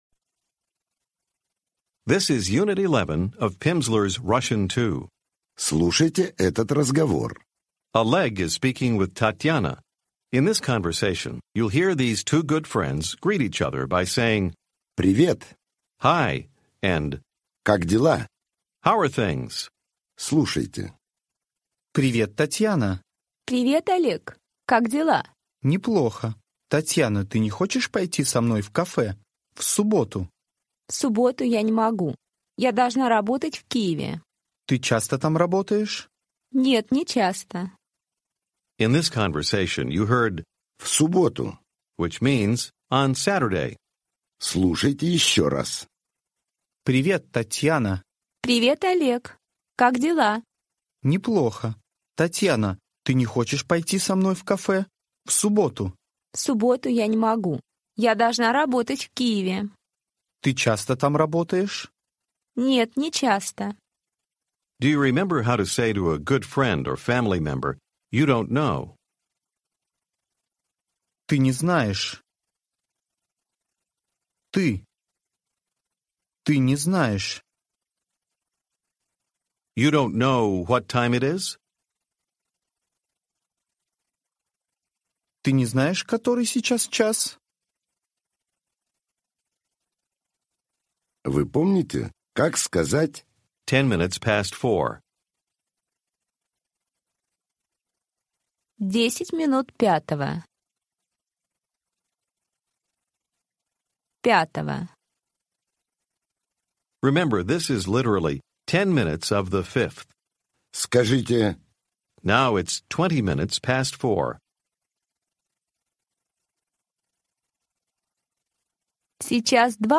Audiobook
This course includes Lessons 11-15 from the Russian Level 2 program featuring 2.5 hours of language instruction. Each lesson provides 30 minutes of spoken language practice, with an introductory conversation, and new vocabulary and structures.